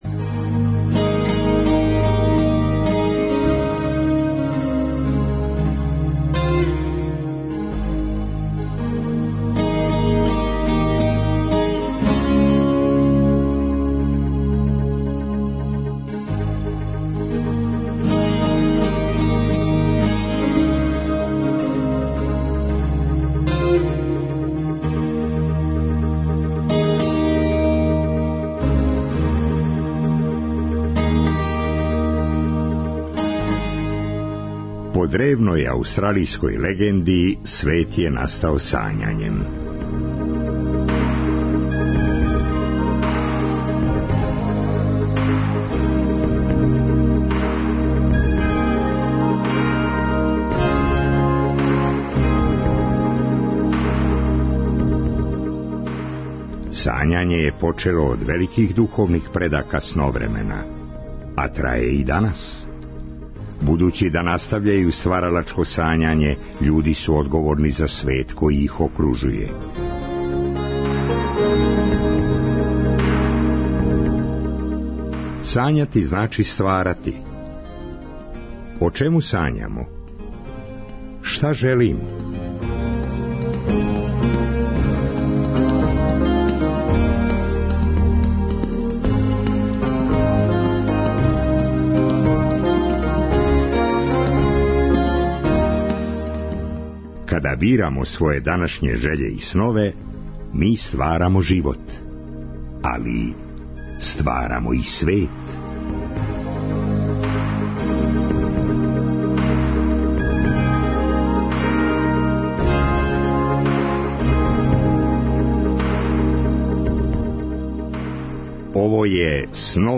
У трећем и четвртом сату емисије истражујемо свет реге музике.